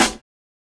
SNARE CRISP KNOCK.wav